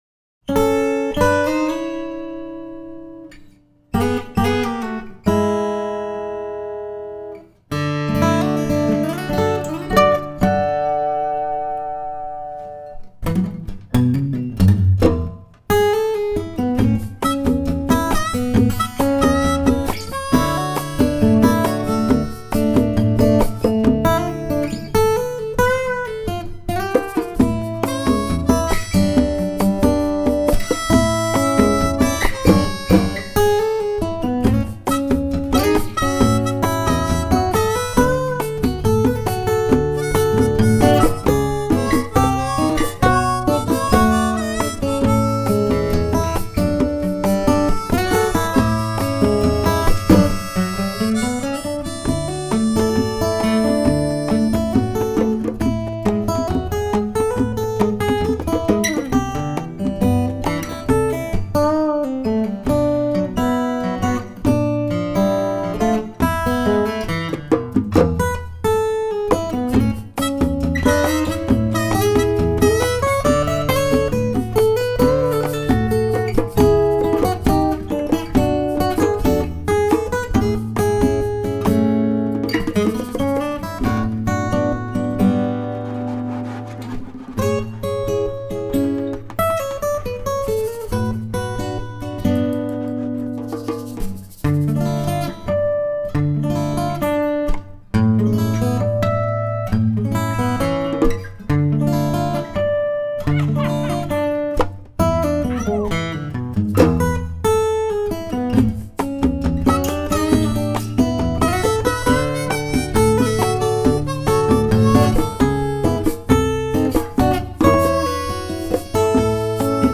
新世紀音樂的吉他演奏家
用吉他和手風琴